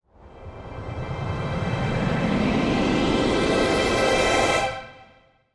Base game sfx done
Anticipation Slot Game.wav